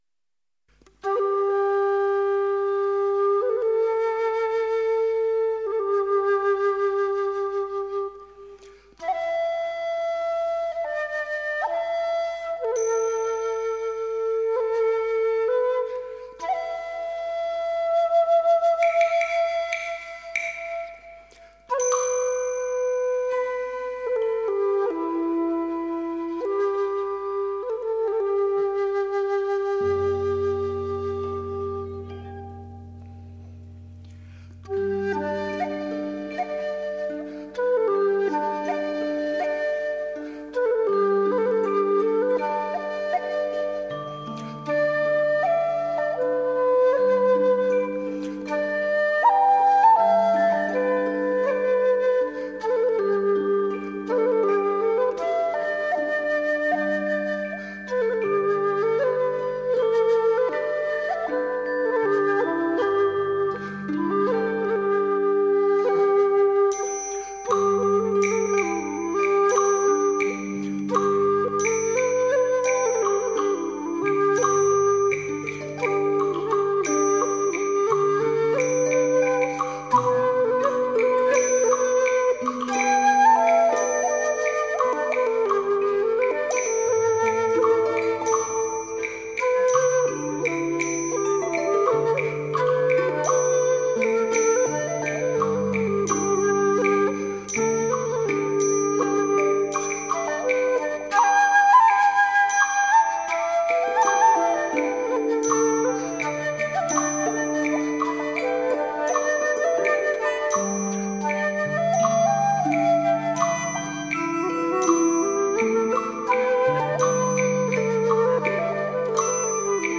(箫) / 古曲
笙、箜篌及打击乐伴奏